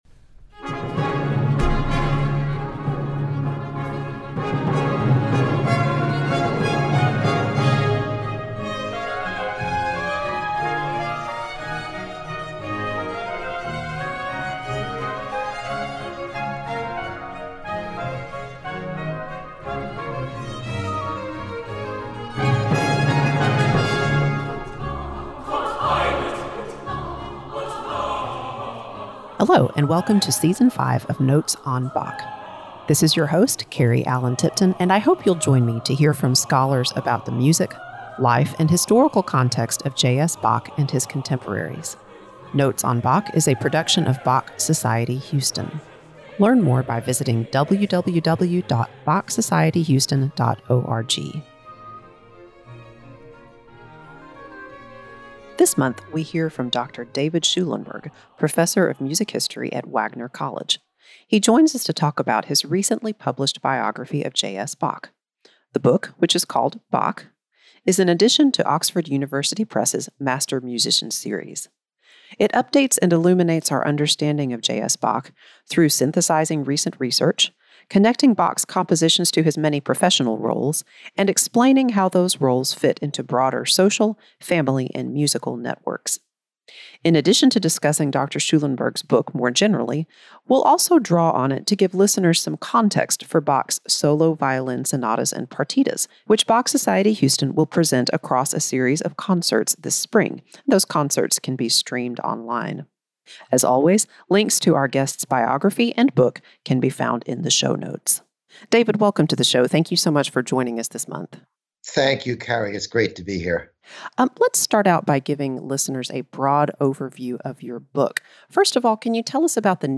Podcast on Bach and the Six Solos for violin